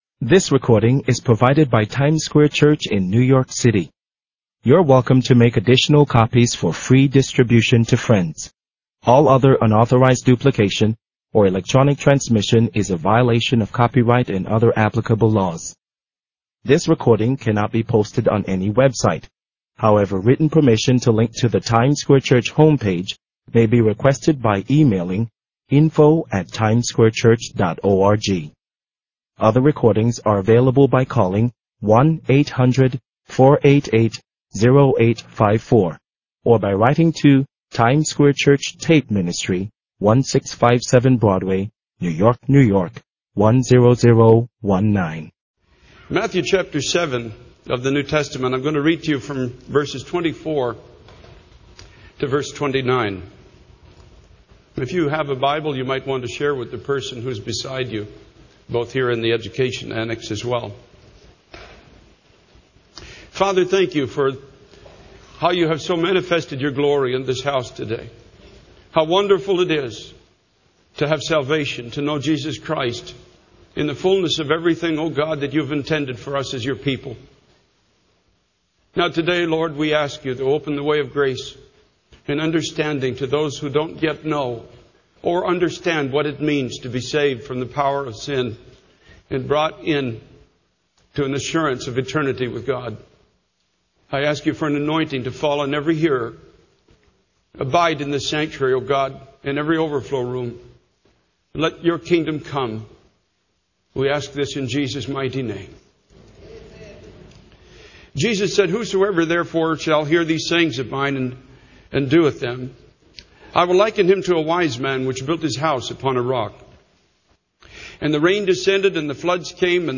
In this sermon, the speaker emphasizes that everything we long for in life, such as purpose, direction, and stability, can be found in Jesus.
He highlights the love and grace of Jesus, who took upon himself the punishment for our sins and offers forgiveness and a new life. The speaker passionately urges the audience to taste and see that God is good and to embrace the new life of forgiveness that Jesus offers.